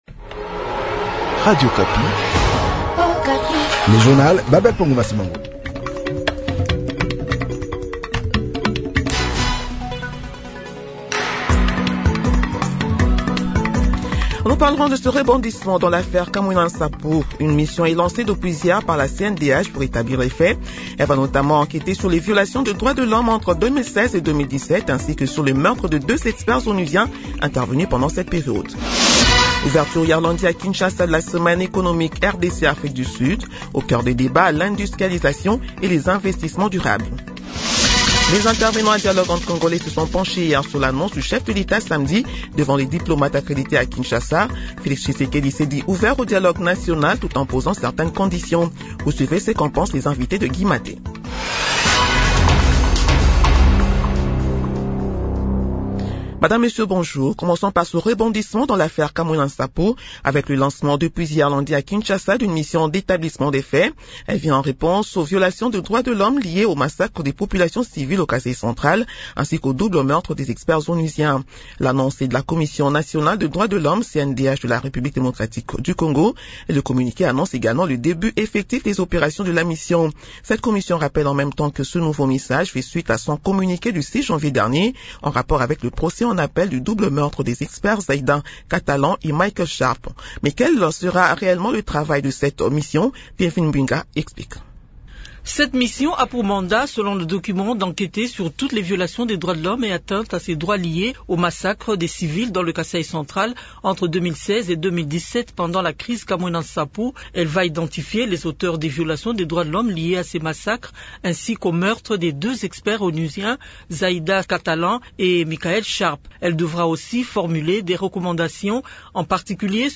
Journal de 7 heures du mardi 03 février 2026